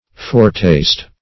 Foretaste \Fore"taste`\, n.